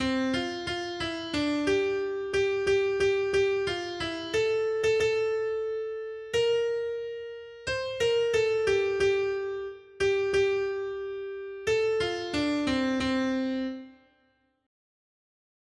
Noty Štítky, zpěvníky ol291.pdf responsoriální žalm Žaltář (Olejník) 291 Skrýt akordy R: Jak milý je tvůj příbytek, Hospodine zástupů! 1.